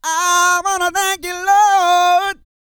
E-GOSPEL 250.wav